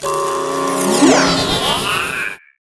resteleport.wav